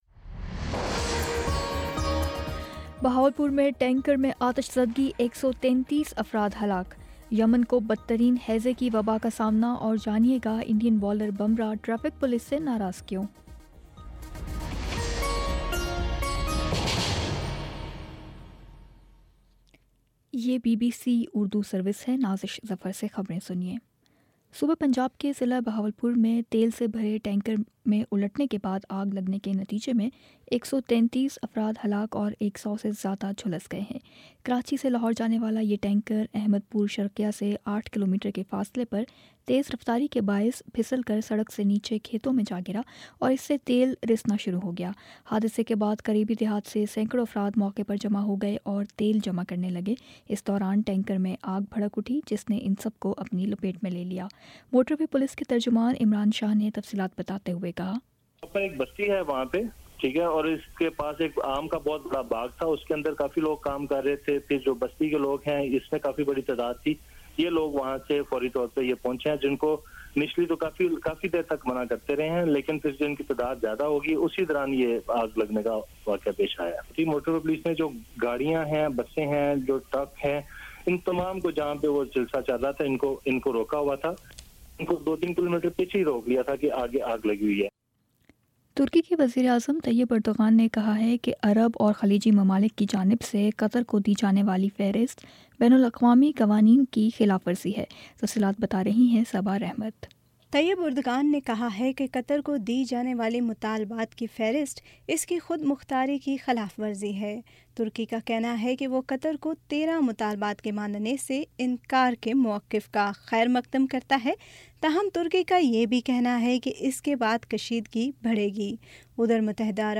جون 25 : شام سات بجے کا نیوز بُلیٹن